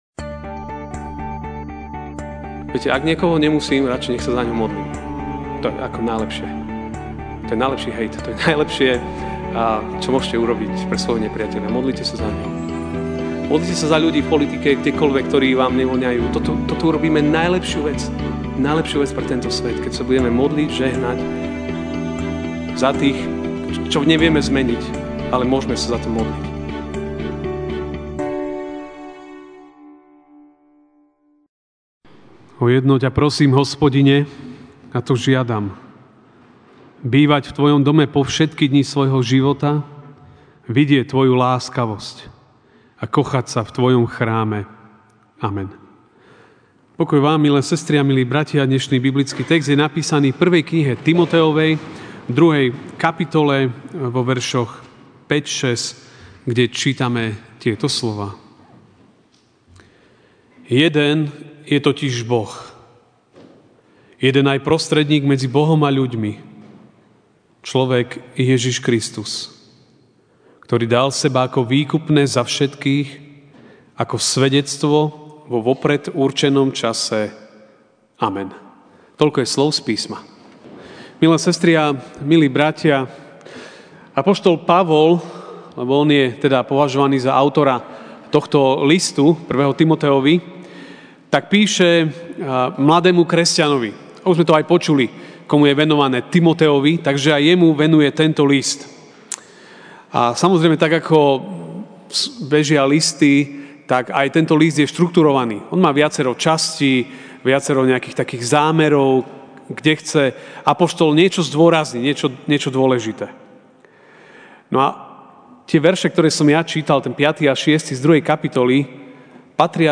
apr 07, 2019 Iba Jeden MP3 SUBSCRIBE on iTunes(Podcast) Notes Sermons in this Series Ranná kázeň: Iba Jeden (1Tim 2, 5-6) Jeden je totiž Boh, jeden aj Prostredník medzi Bohom a ľuďmi, človek Ježiš Kristus, ktorý dal seba ako výkupné za všetkých ako svedectvo vo vopred určenom čase.